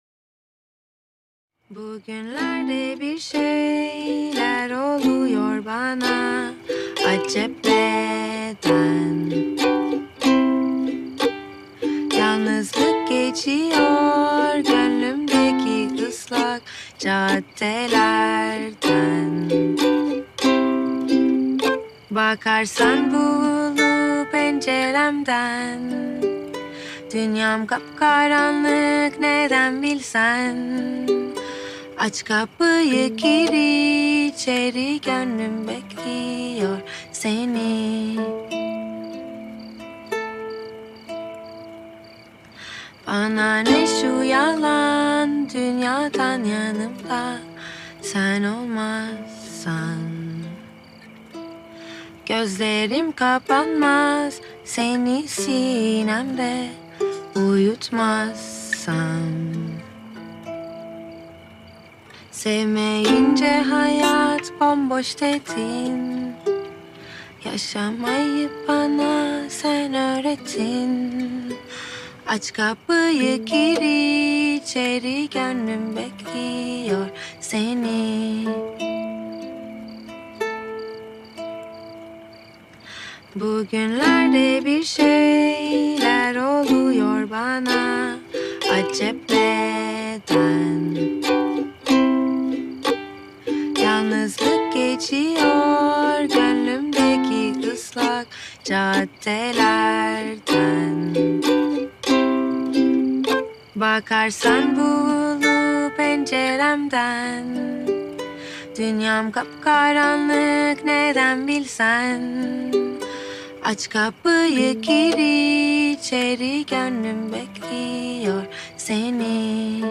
dizi müziği, duygusal huzurlu rahatlatıcı şarkı.